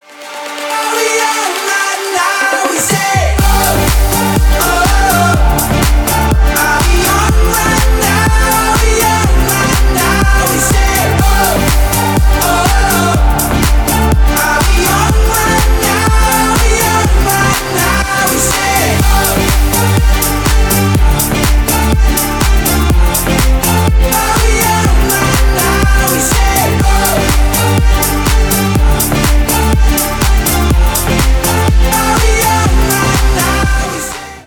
• Качество: 320 kbps, Stereo
Танцевальные
клубные
громкие